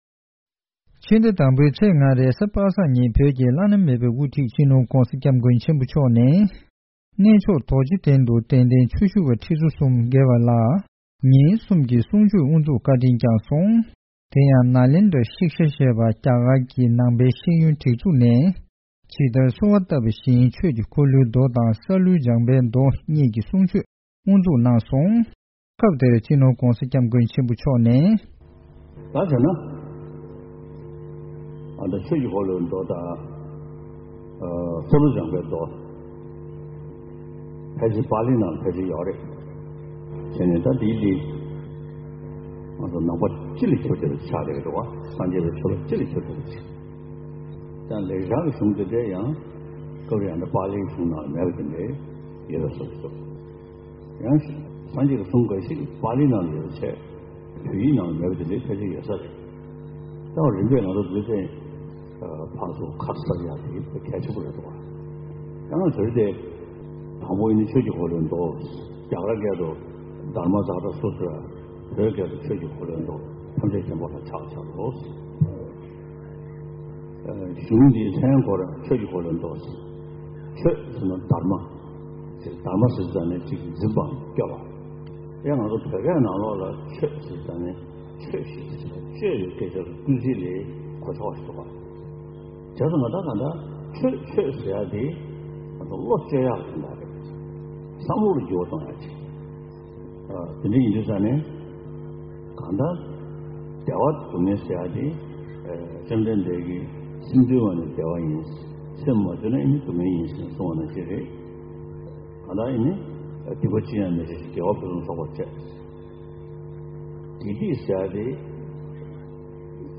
རྡོ་རྗེ་གདན་ནས་བཏང་བའི་གནས་ཚུལ་ཞིག